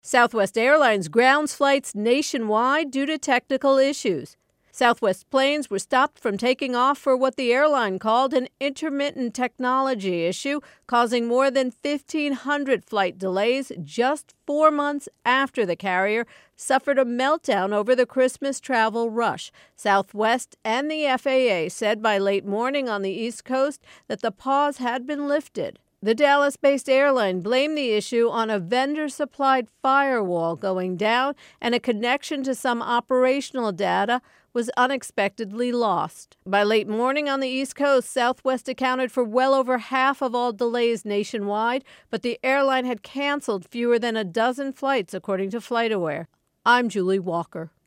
reports on Southwest Airlines Flights.